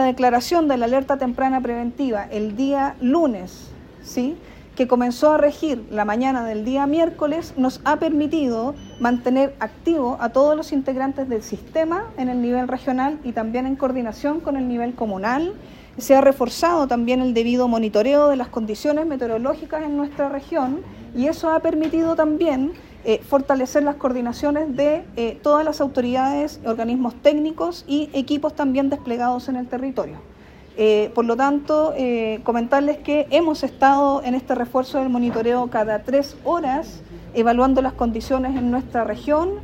La directora regional de SENAPRED Tarapacá, Patricia Montenegro, informó que se mantiene vigente una Alerta Temprana Preventiva debido a condiciones meteorológicas, especialmente por vientos en sectores cordilleranos.